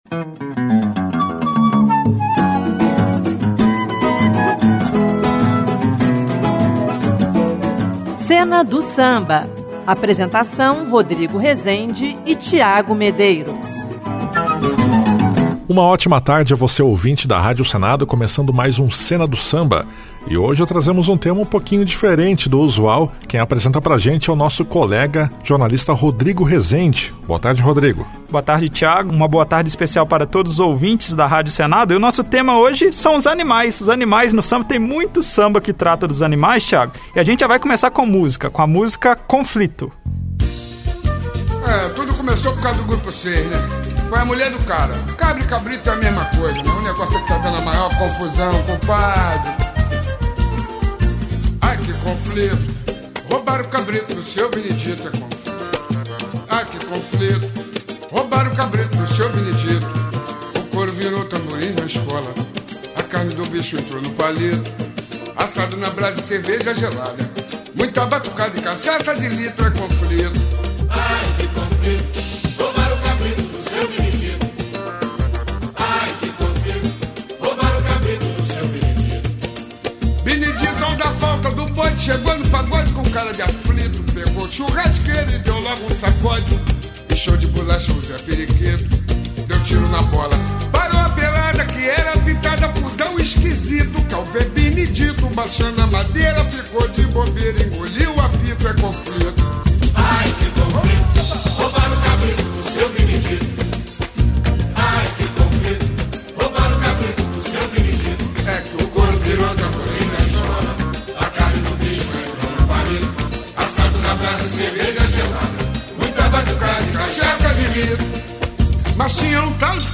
Sambas clássicos de carnaval